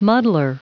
Prononciation du mot muddler en anglais (fichier audio)
Prononciation du mot : muddler